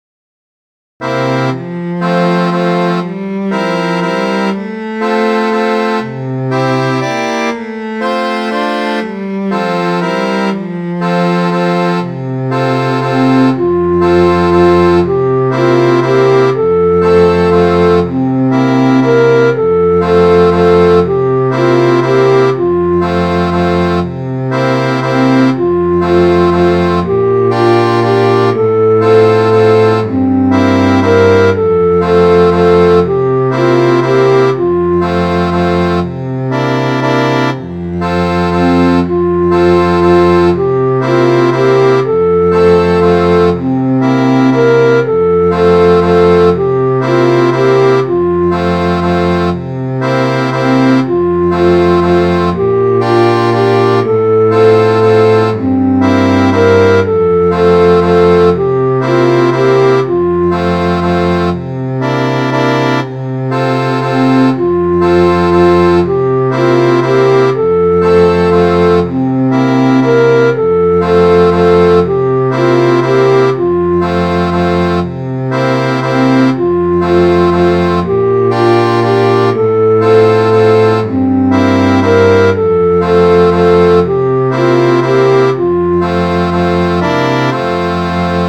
Midi File, Lyrics and Information to Paddy Doyle's Boots